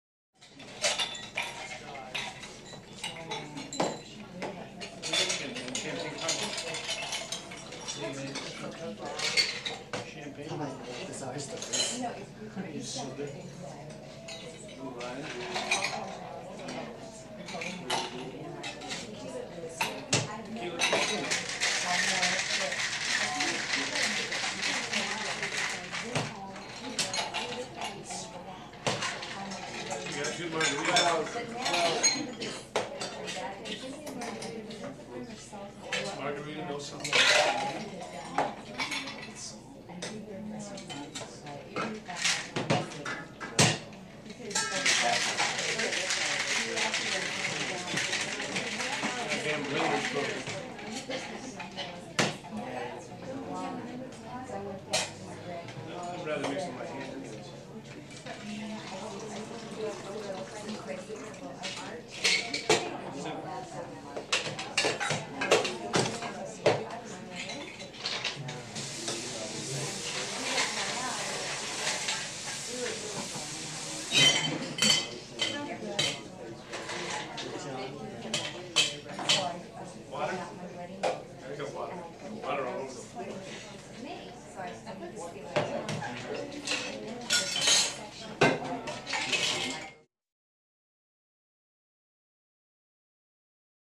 Pub | Sneak On The Lot
Pub - Busy, Cash Register, Glass Clinks
Pub - Light Walla, People Ordering Drinks, Bartender